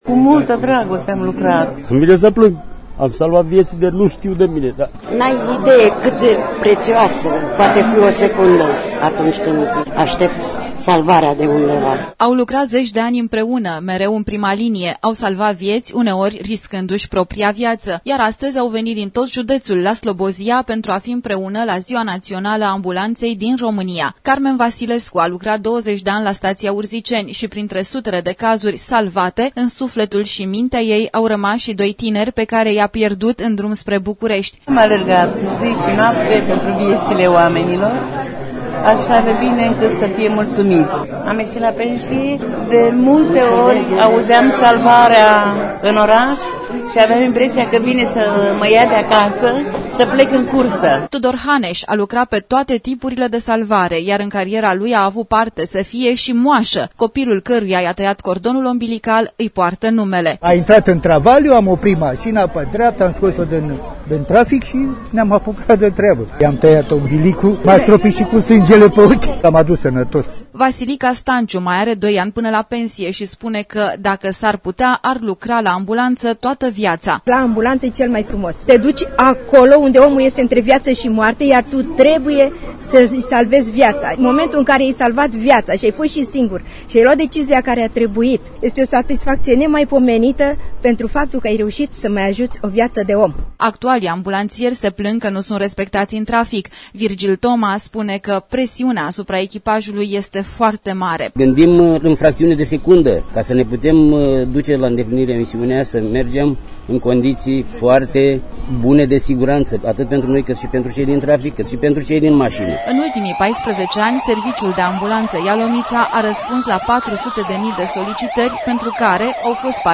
Asistenţii şi şoferii pensionari care au lucrat la Salvare au fost astăzi omagiaţi în Ialomiţa, cu ocazia Zilei Naţionale a Ambulanţei din România!
reportaj audio Ambulanta Ialomita
reportaj-audio-Ambulanta-Ialomita.mp3